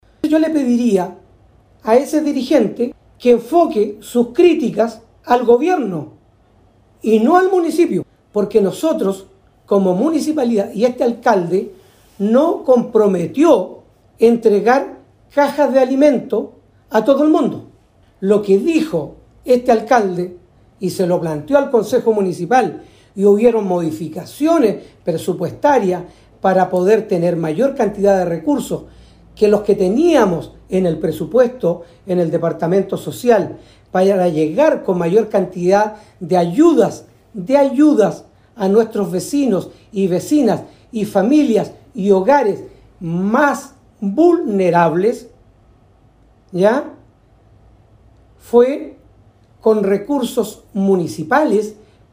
Sobre la manifestación de estos pobladores, aquejados por la nula ayuda en materia de alimentos básicos durante la emergencia, respondió el alcalde Carlos Gómez.